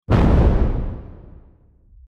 explosion11.wav